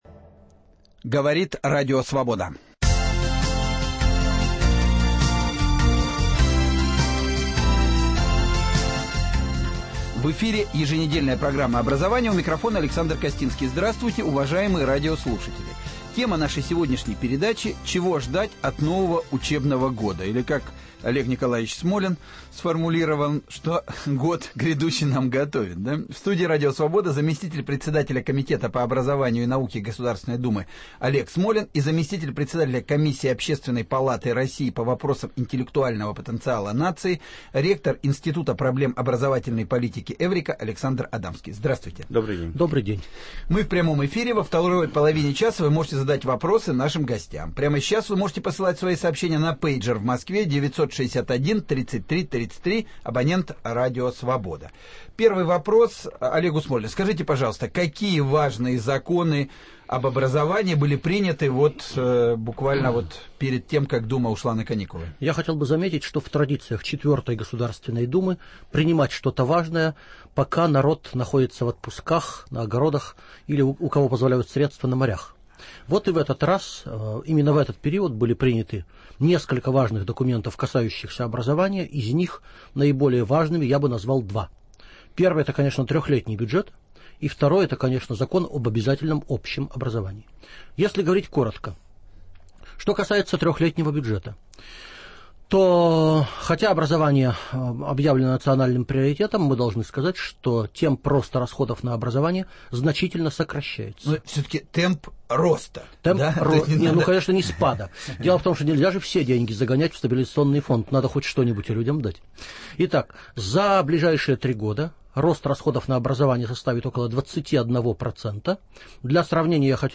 Чего ждать от нового учебного года. В студии Радио Свобода